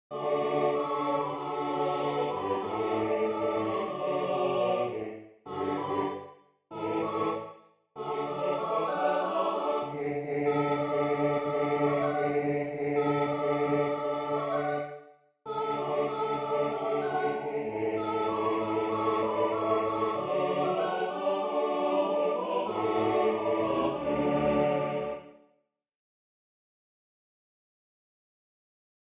This is a SATB choir plus congregation arrangement.
Voicing/Instrumentation: SATB